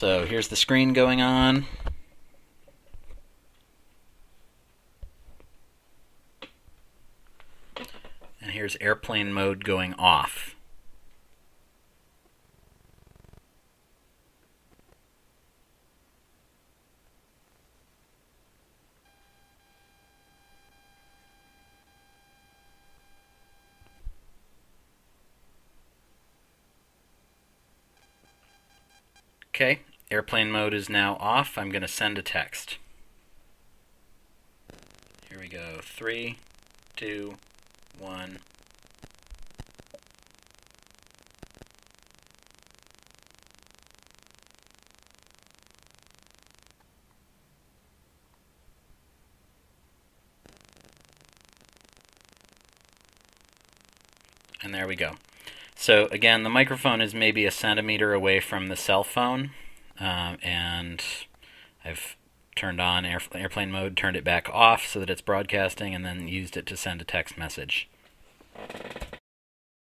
• Mic: Naiant X-R hypercardioid
When I spoke, I was about a foot behind the mic, so my voice was in a pretty weak part of the mic’s pickup pattern. I turned off Airplane Mode (turning the phone’s radio back on), let it negotiate a signal, then sent a text message.
When it’s normalized, there’s definitely some interference going on there. Looking at the VU meters in REAPER, I found that the voice peaks at -1 dB, the Airplane Mode interference hangs out around -38 dB but spikes briefly up to -24 dB, and the sending-text interference averages -32 dB but spikes up to -23 dB.
Naiant-X-R-RF-cell-phone-test.mp3